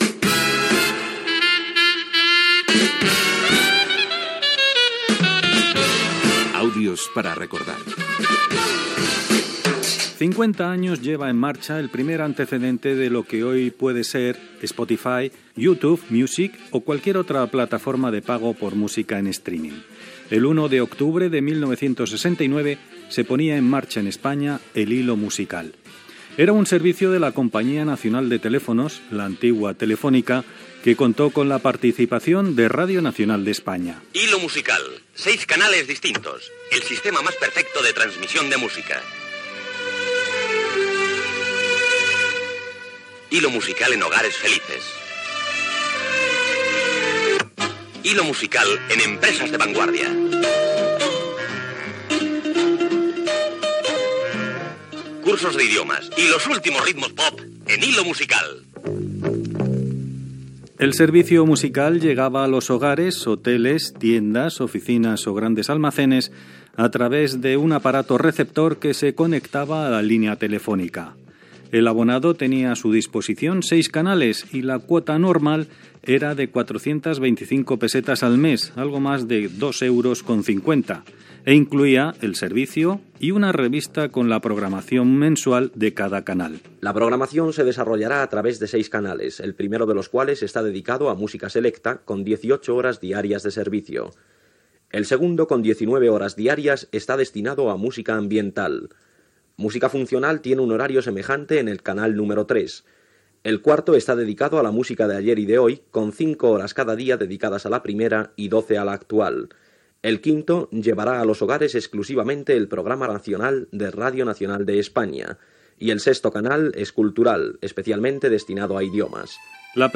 Careta del programa, espai dedicat a l'Hilo Musical de Radio Nacional de España